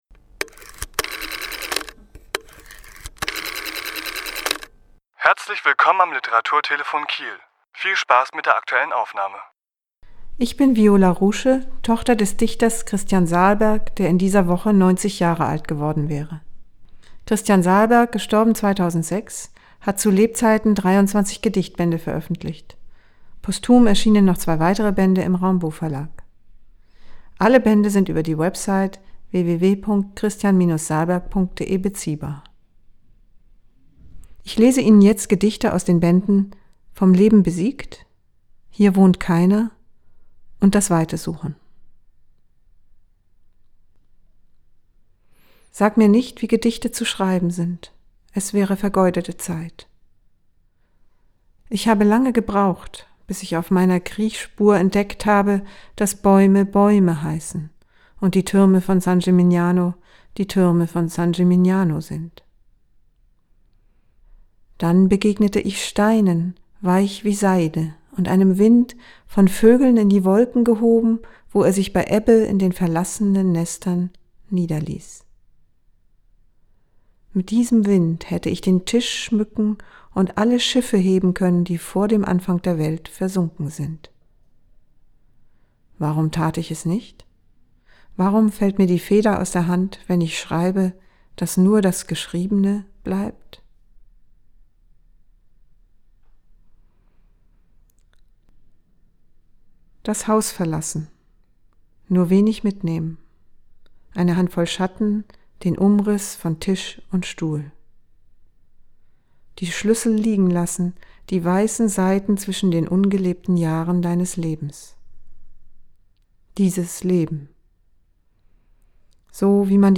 Autor*innen lesen aus ihren Werken